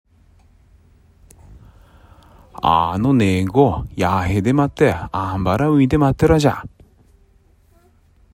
津軽方言：古代の発音
津軽方言のを聞くと、古代日本語の音声の一部が、現代の津軽方言に受け継がれていることが分かります。